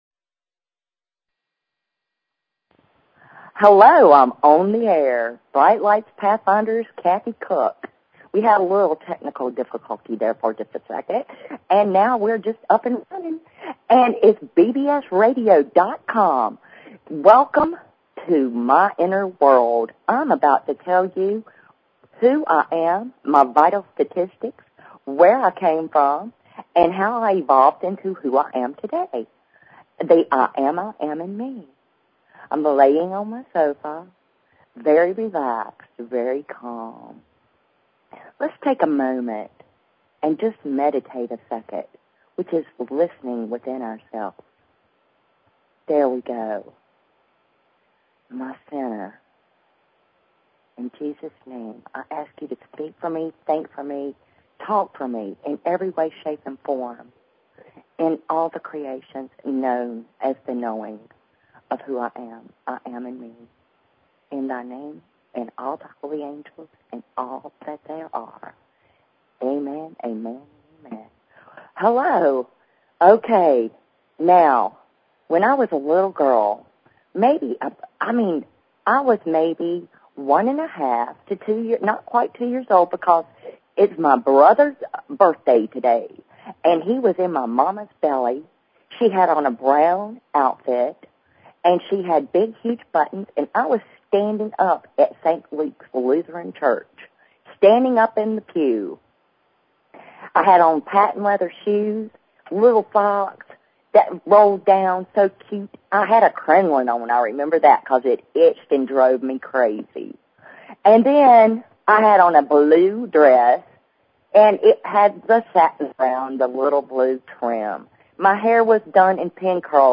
Talk Show Episode, Audio Podcast, Brightlights_Pathfinders and Courtesy of BBS Radio on , show guests , about , categorized as